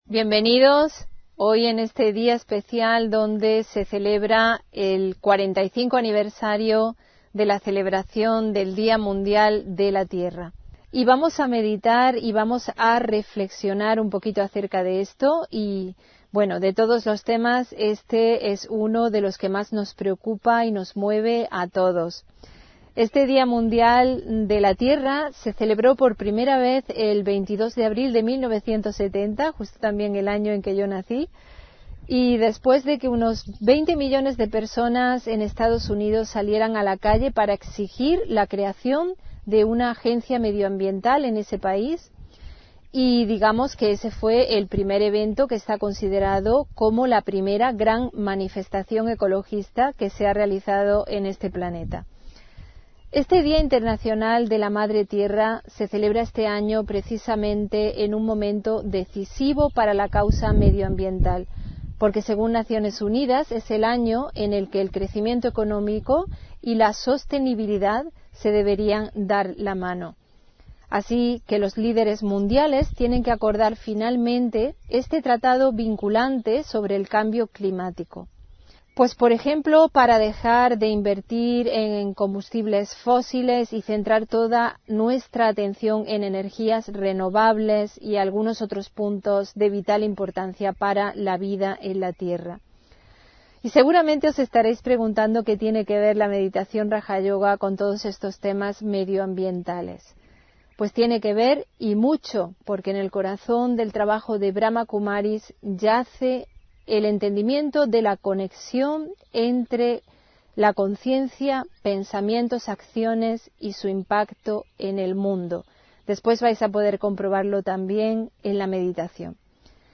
Meditación y conferencia: Sanar y proteger la tierra (22 Abril 2024)